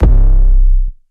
808 [Jump].wav